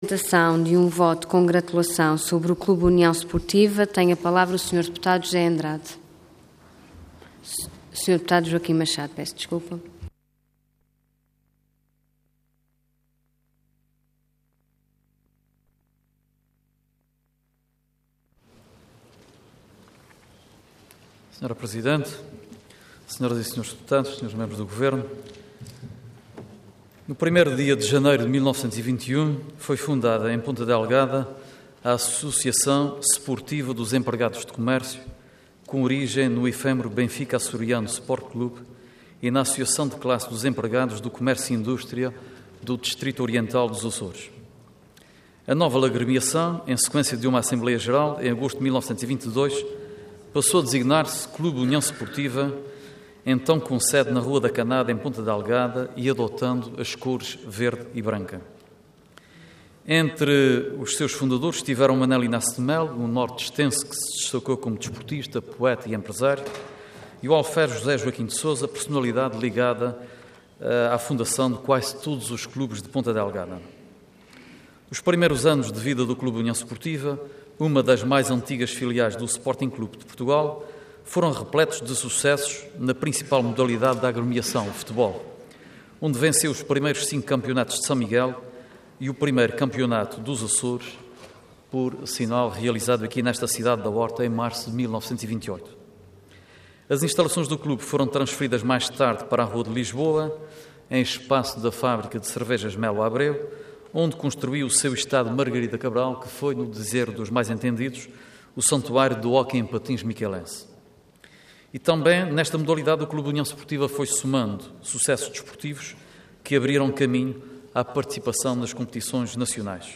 Intervenção Voto de Congratulação Orador Joaquim Machado Cargo Deputado Entidade PSD